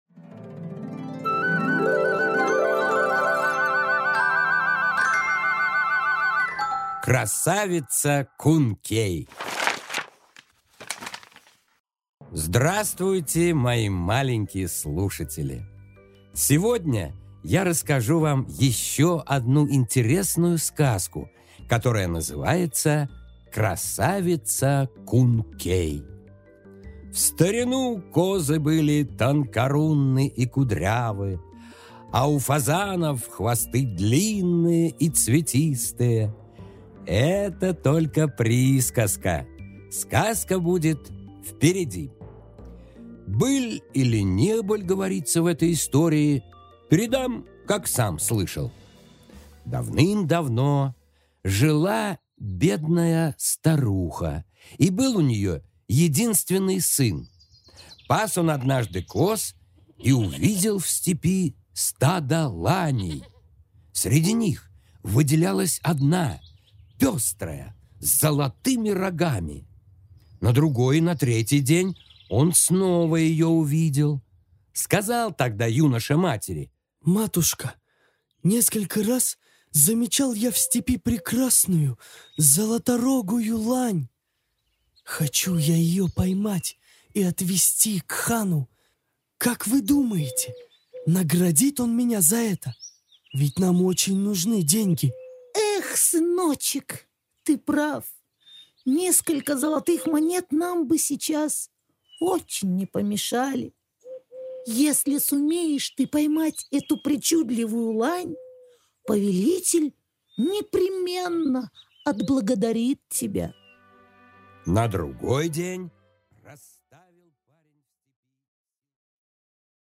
Аудиокнига Красавица Кункей